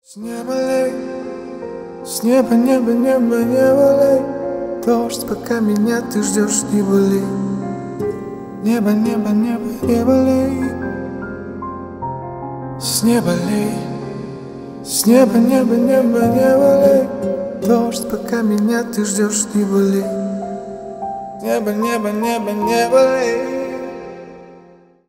Поп Музыка
грустные # кавер